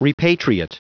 Prononciation du mot repatriate en anglais (fichier audio)
Prononciation du mot : repatriate